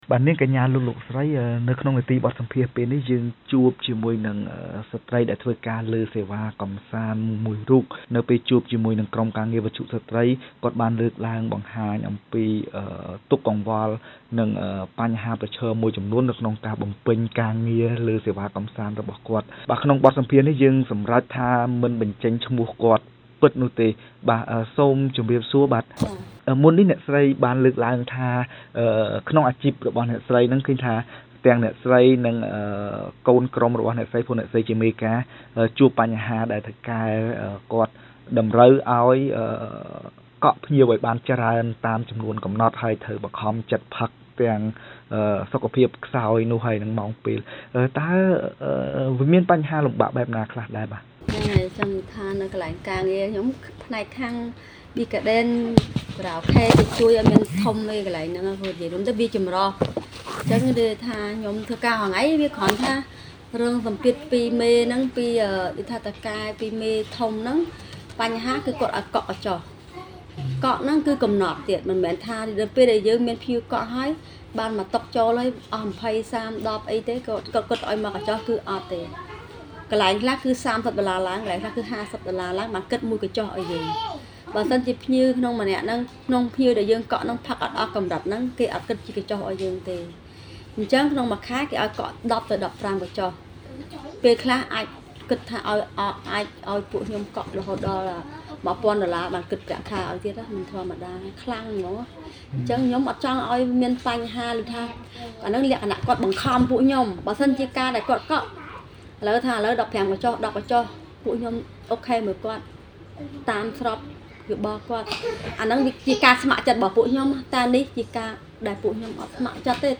ស្ត្រីធ្វើការលើសេវាកម្សាន្តដែលវិទ្យុស្ត្រីបានជួបសម្ភាសន៍ បានលើកឡើងពីបញ្ហាប្រឈមដែលខ្លួនកំពុងជួបប្រទះក្នុងអជីព។